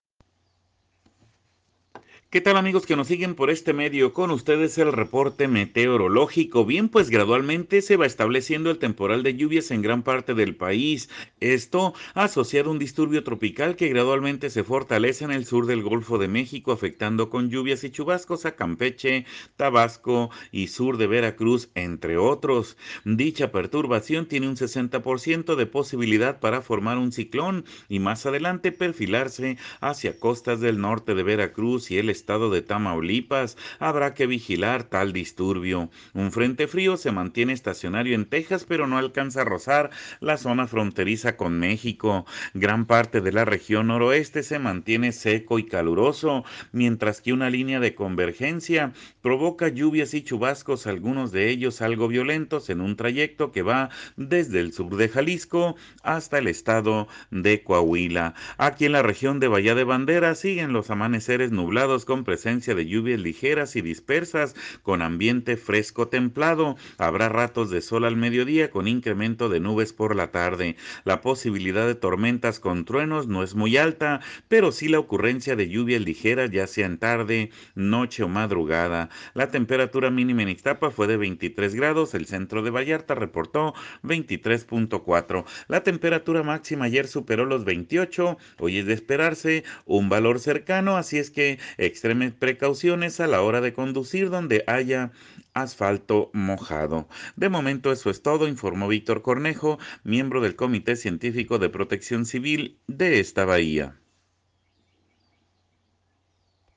escuche al meteorólogo